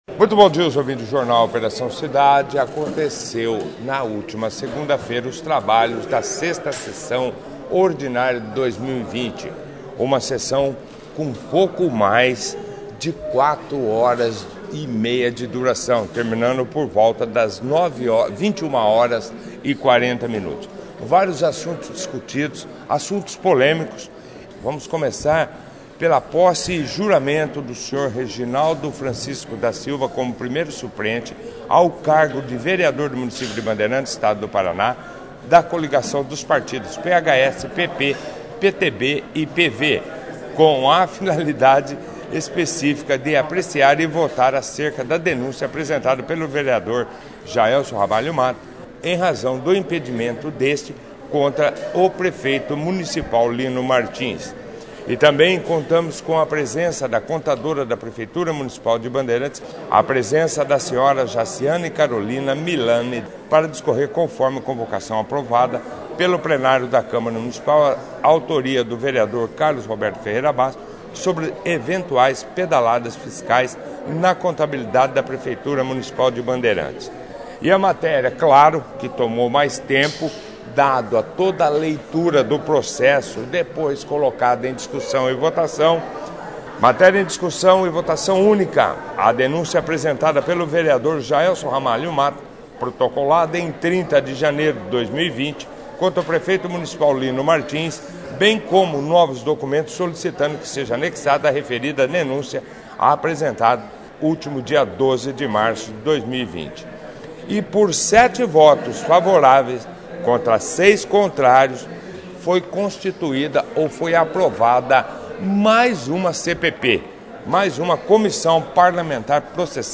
Destaques da sexta sessão ordinária da Câmara Municipal de Bandeirantes
6ª-Sessão-Ordinaria-Camara-de-Vereadores.mp3